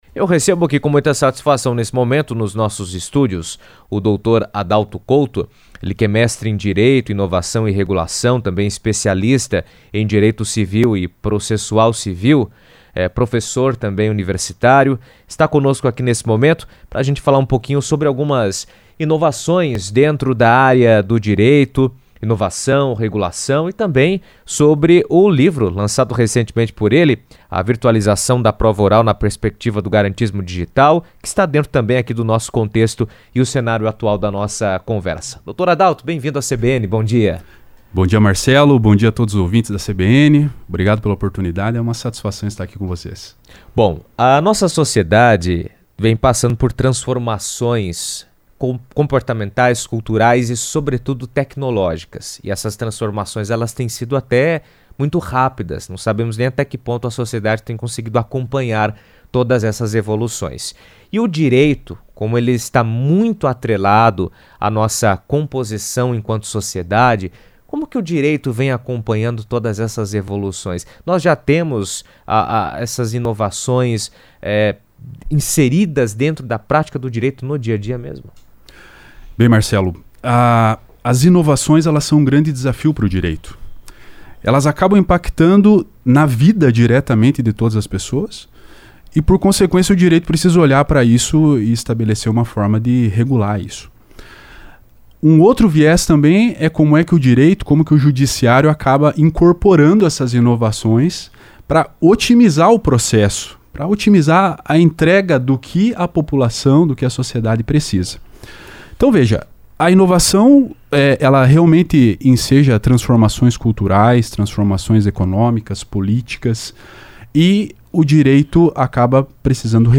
Editoriais
A relação entre inovação e regulação tem imposto novos desafios ao Direito, especialmente diante da digitalização de processos e da incorporação de tecnologias no sistema de Justiça. Em entrevista à CBN